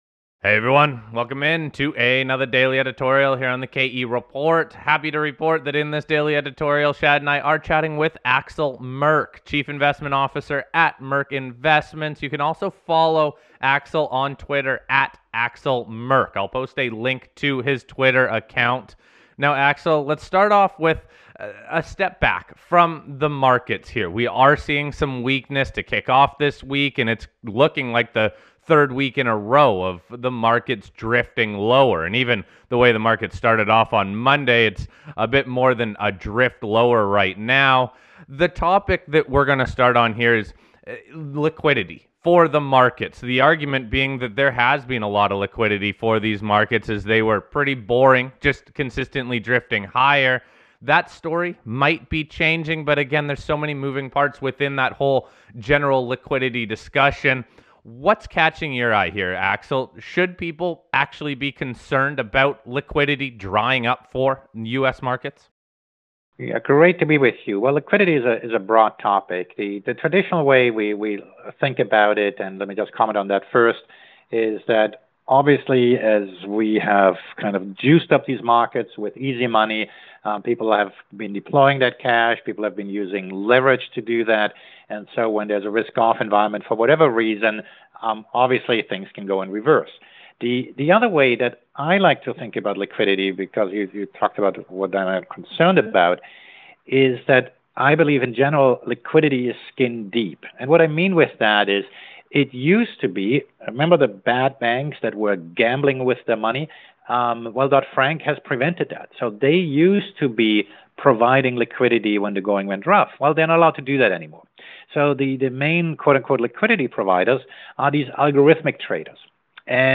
To wrap up the interview we look at the precious metals markets.